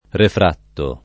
refratto [ refr # tto ]